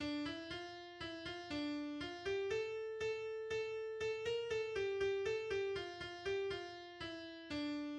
chanson en laisse enfantine
La mélodie est inspirée de celle de Travadja la moukère.